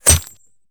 bullet_impact_glass_03.wav